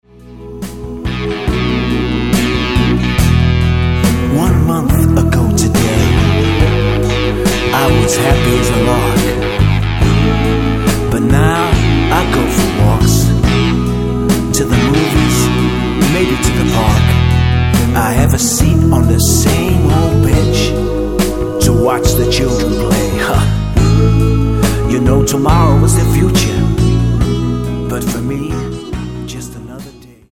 Tonart:E Multifile (kein Sofortdownload.
Die besten Playbacks Instrumentals und Karaoke Versionen .